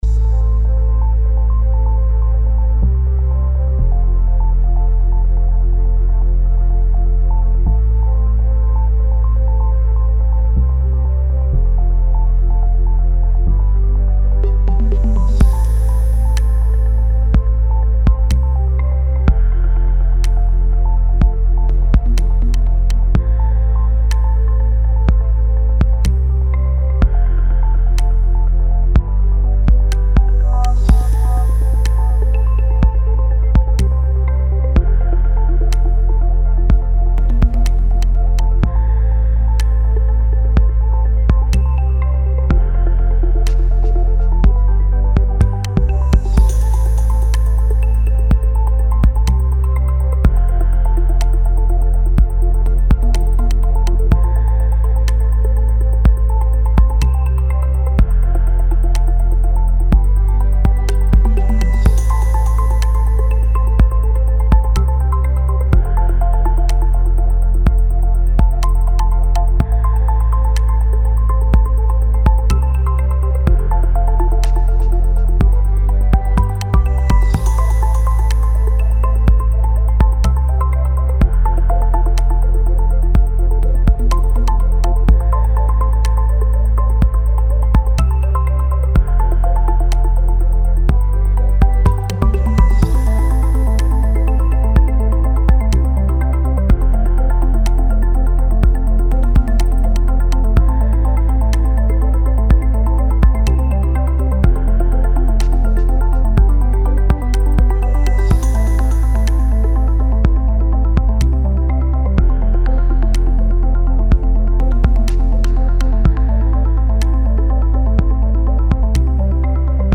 Musik Latar Belakang.mp3